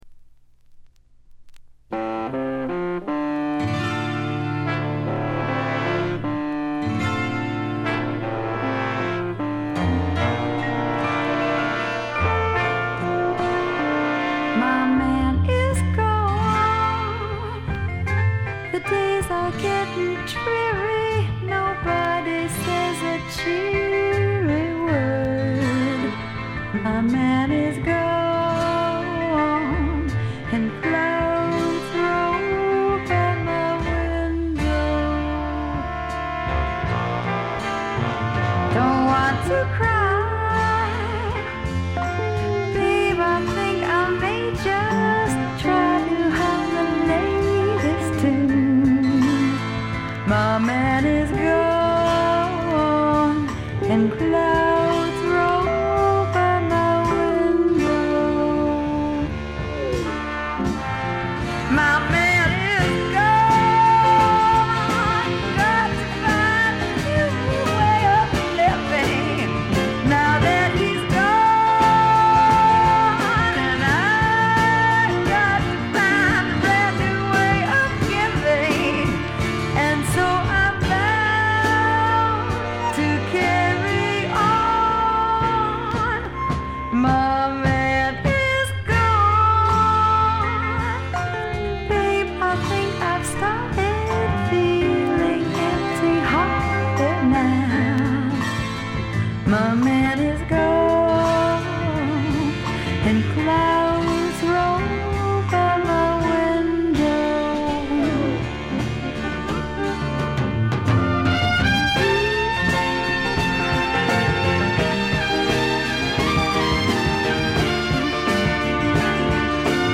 散発的なプツ音少し。
スワンプ、R&B、ジャズ、ブルース、サイケのごった煮で
ホーン・セクションを含む大世帯のバンドを狭いライヴ酒場で聴いているような強烈な圧力があります。
試聴曲は現品からの取り込み音源です。
Vocals